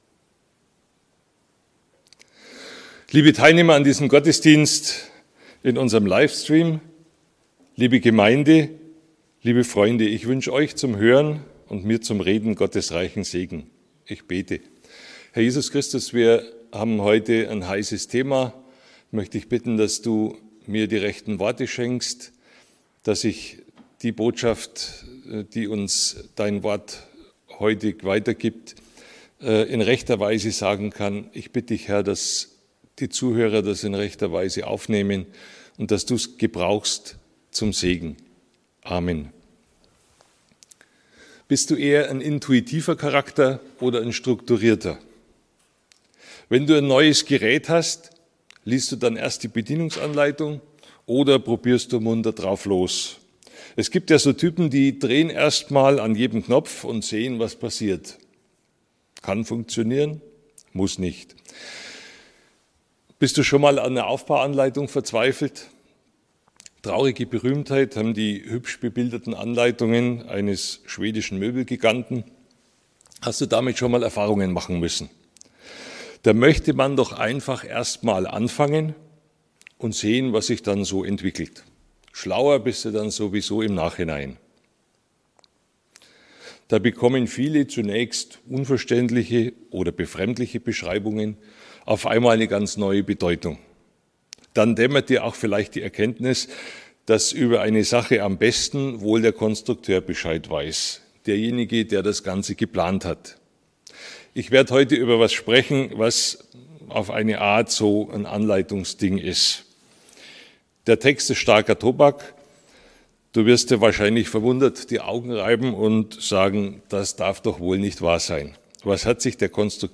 Gewinnendes Verhalten in der Ehe – EFG Bayreuth